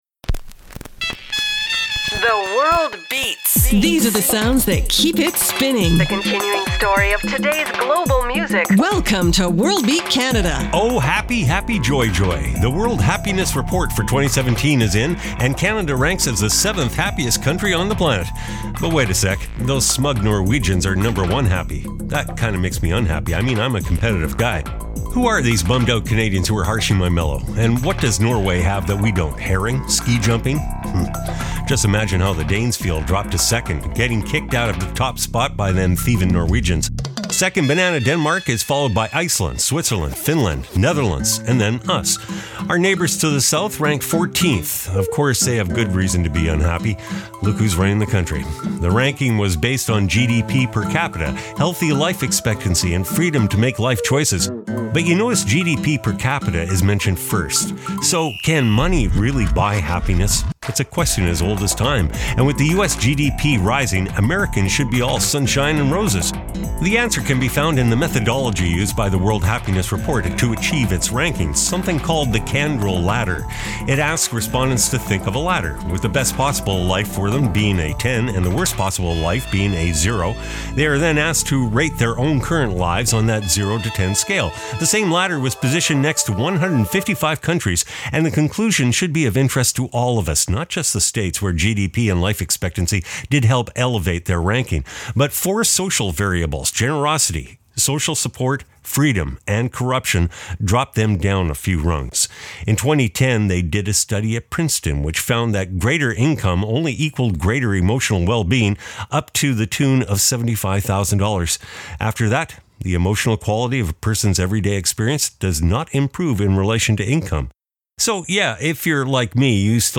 exciting contemporary global music alternative to jukebox radio